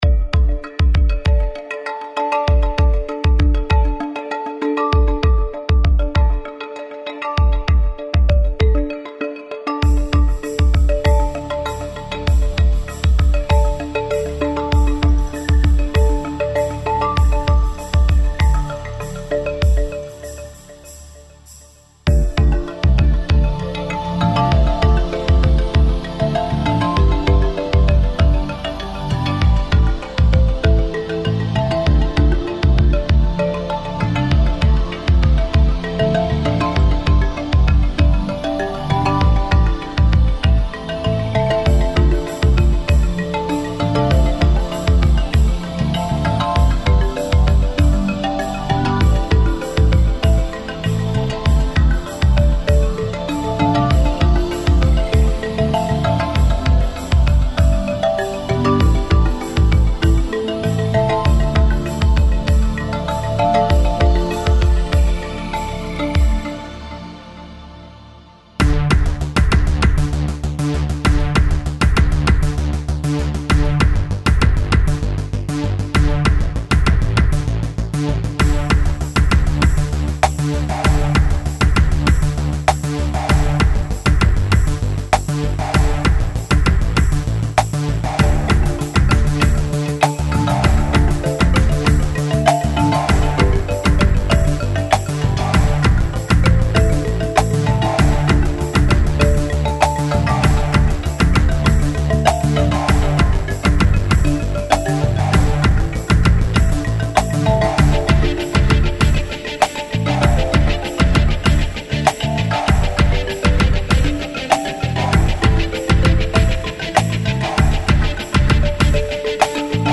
הורדות ללא הגבלה של מוזיקה להאזנה קלה ללא RSoyalty.mp3